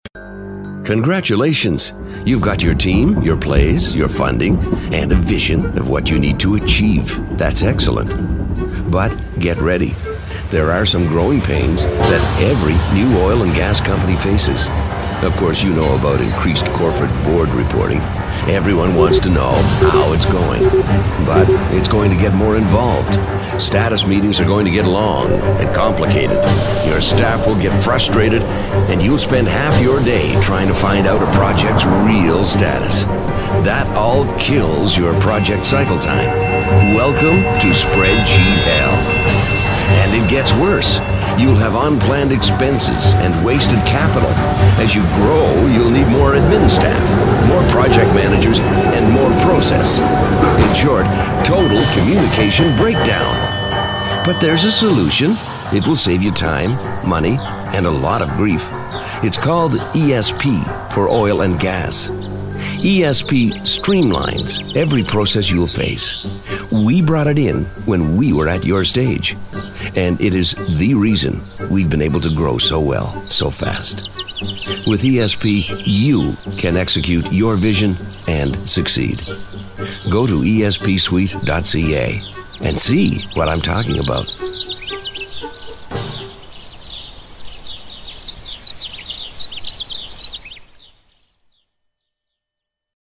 Experienced professional voiceovers, Canadian, American, guaranteed, free auditions
Sprechprobe: Werbung (Muttersprache):